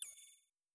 Scene Transition Back.wav